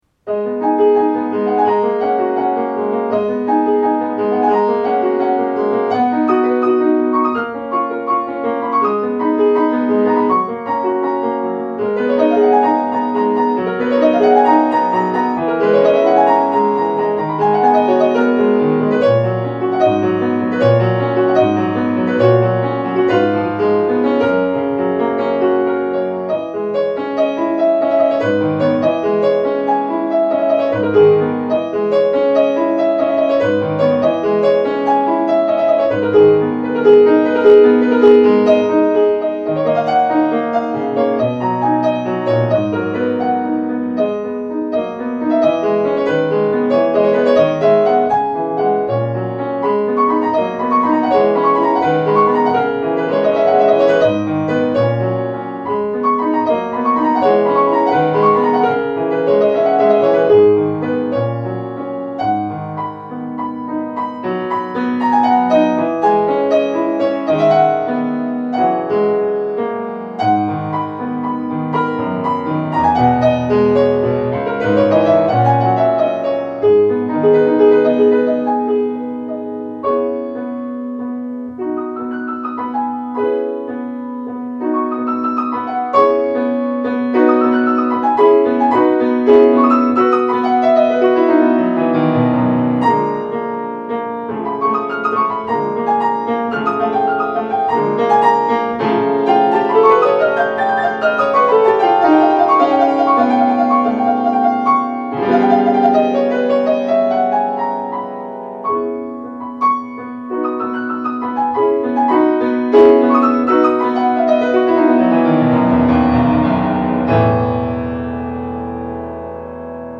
Klavierstücke
gespielt auf einem Feurich 197